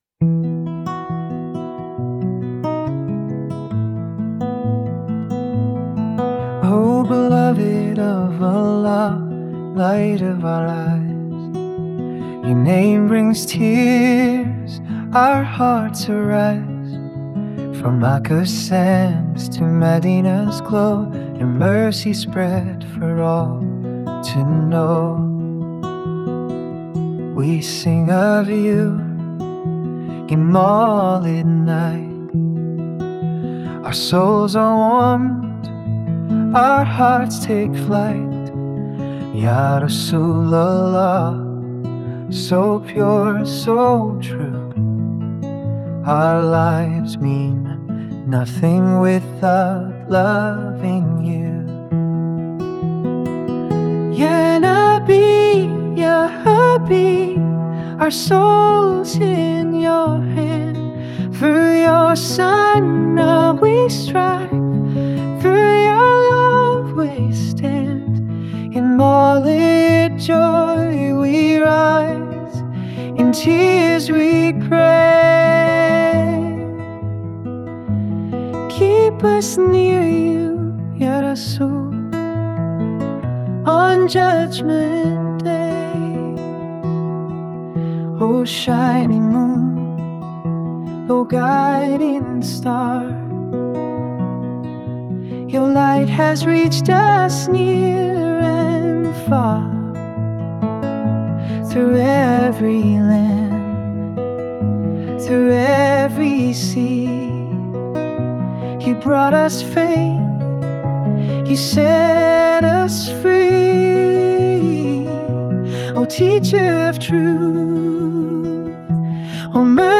A Heartfelt Nasheed for Mawlid
mawlid-nasheed-keep-us-near-you-ya-rasool.mp3